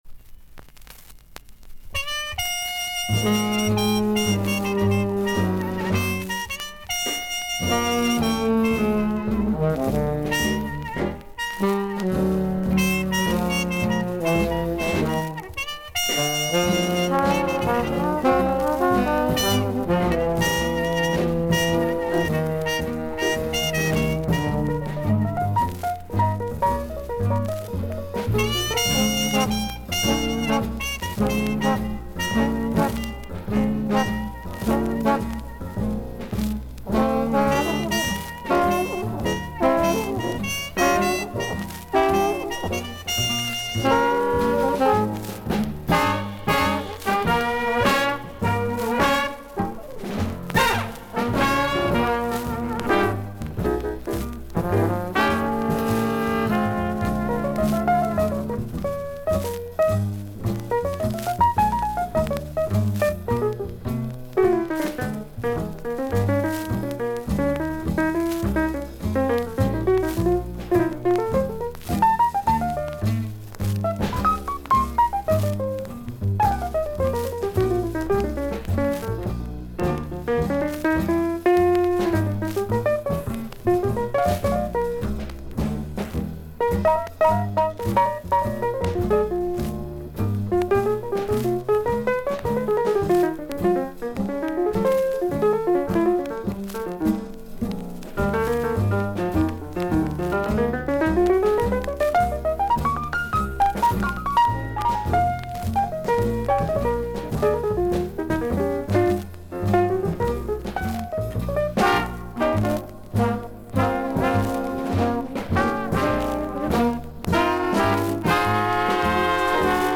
A1最初から後半までサッと周回ノイズと少々軽いパチノイズあり。
少々サーフィス・ノイズあり。クリアな音です。
ジャズ・サックス奏者。